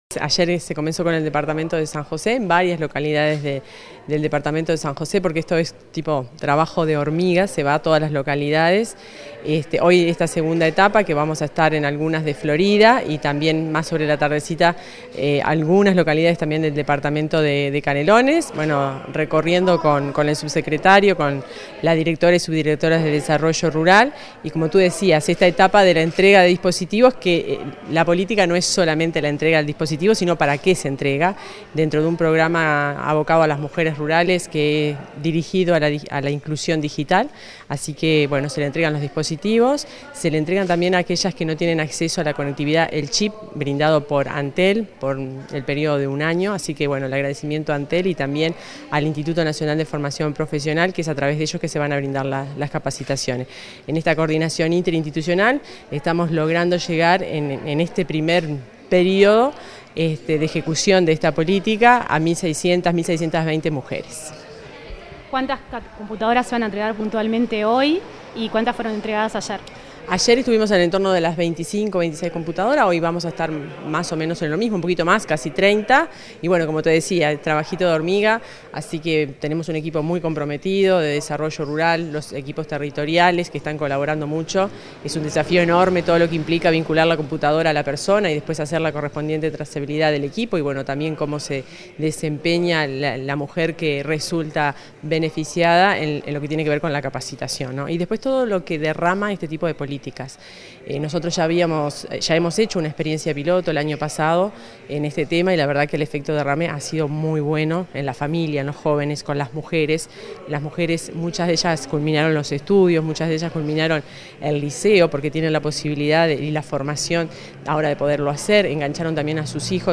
Entrevista a la directora general del MGAP, Fernanda Maldonado